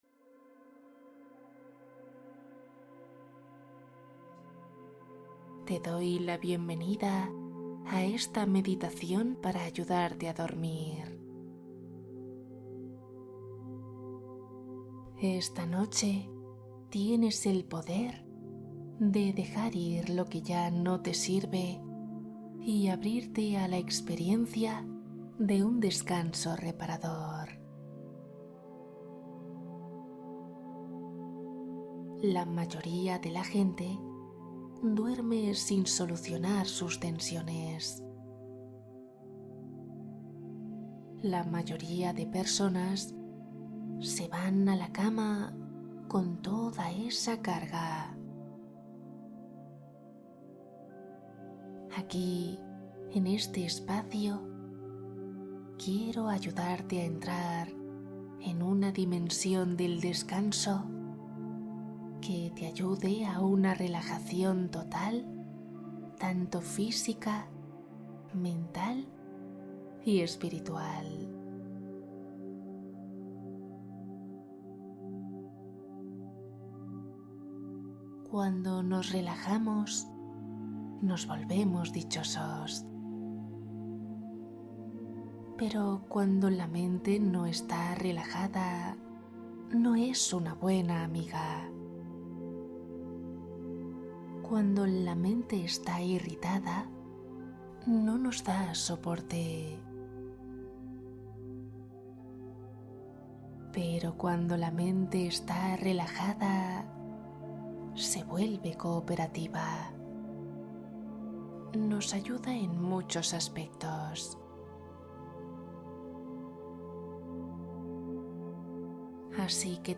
Relajación absoluta Meditación con cuento para sueño profundo